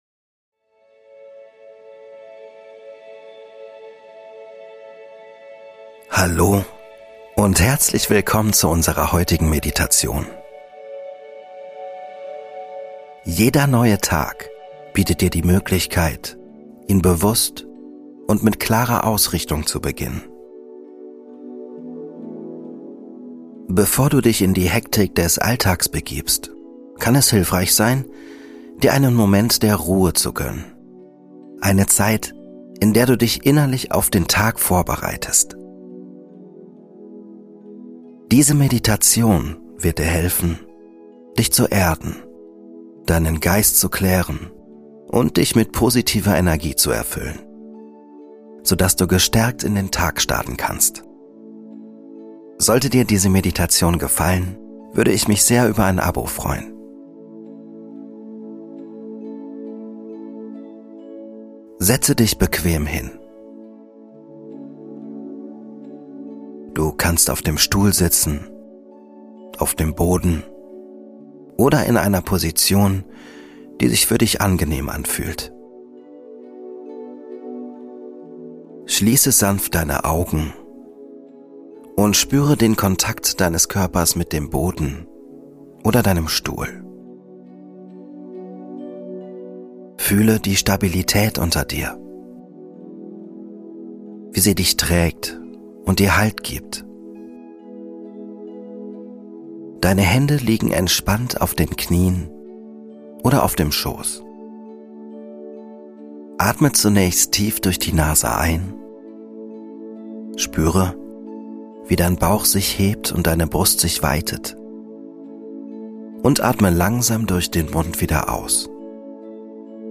Willkommen zu deiner täglichen Morgenmeditation – die perfekte Routine, um kraftvoll und voller positiver Energie in den Tag zu starten. Diese geführte Meditation hilft dir, Ängste und Sorgen loszulassen und dich auf das Hier und Jetzt zu konzentrieren.